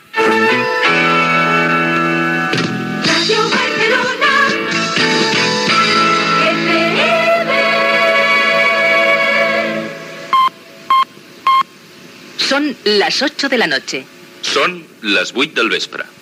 Indicatiu i hora